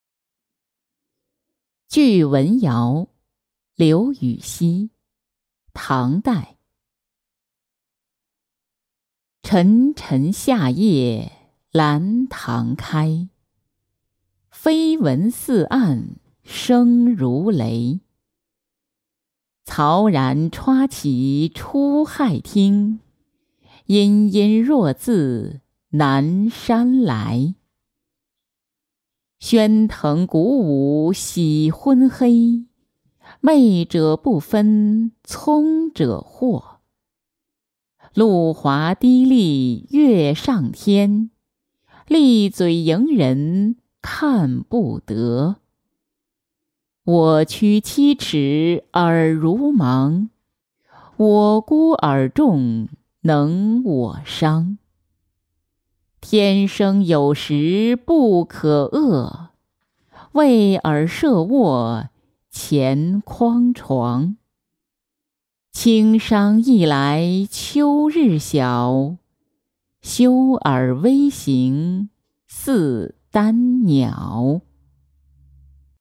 聚蚊谣-音频朗读